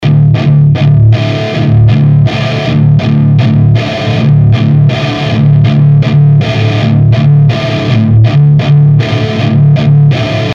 (un boitier de reamp dans un cas et dans l'autre il est remplacé par la sortie casque)
Le rv2 semble plus propre et ready to mix, alors que l'autre est dégueulasse dans les basses, sature...